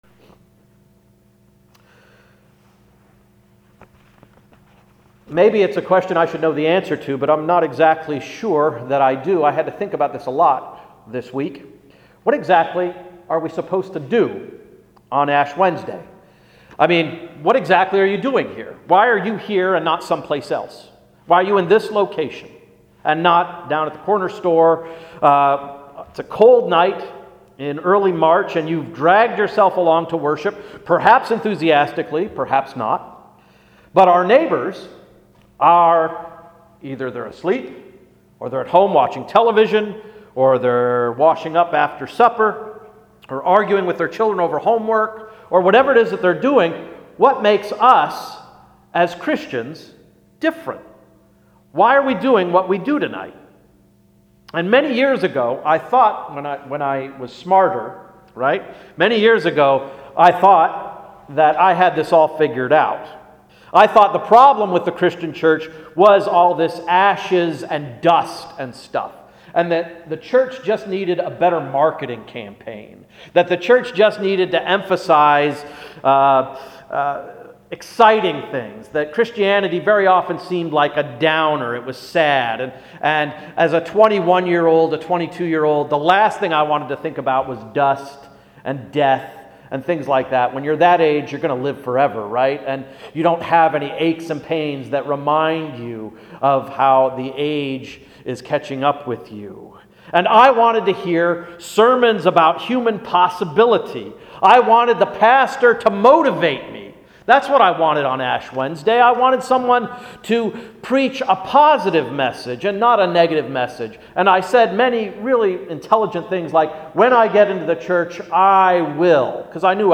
Ash Wednesday Sermon–March 5, 2014